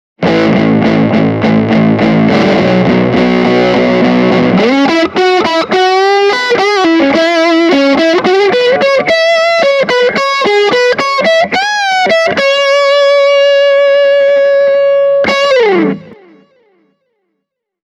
JTM1C on hyvin kermainen ja lämmin, kun taas JMP:llä on tarjolla selkeästi enemmän säröä ja soundi on rouheampi.
Marshall JTM1C – Hamer Studio Custom/gain täysillä